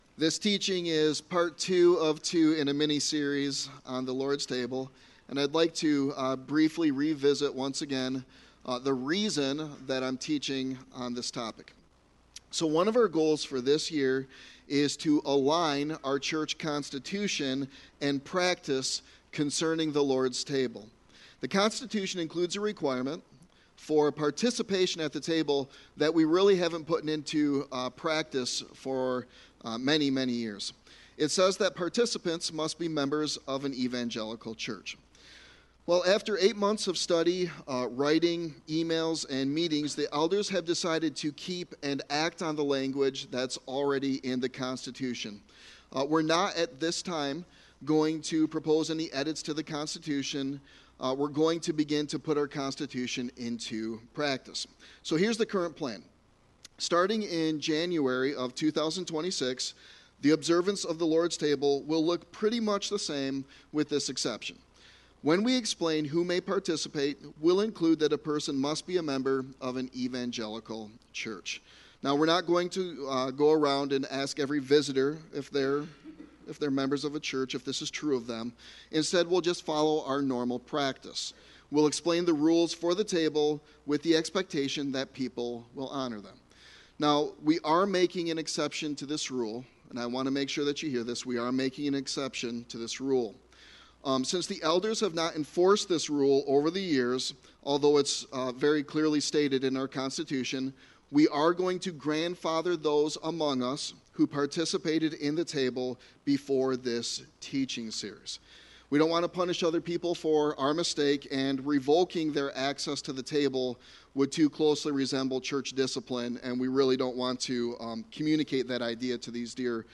Scripture Reading: Ephesians 4:1-6